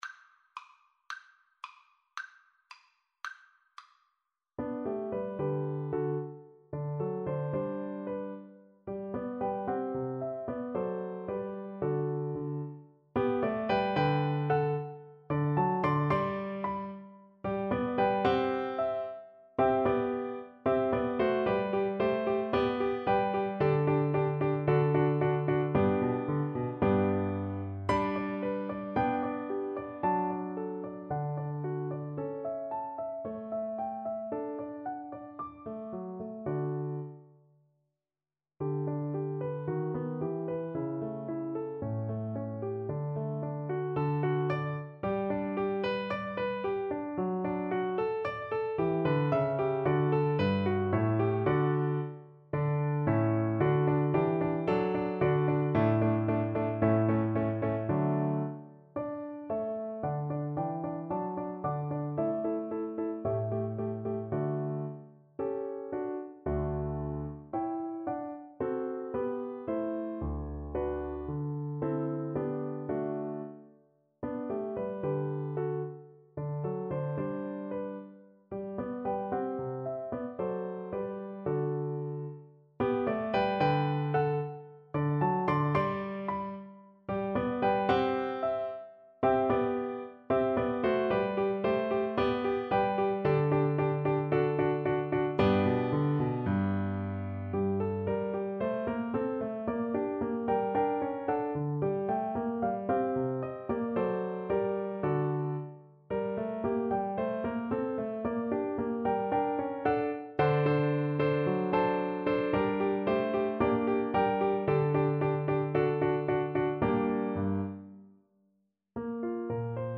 2/4 (View more 2/4 Music)
~ = 100 Allegretto (=112)
Classical (View more Classical Viola Music)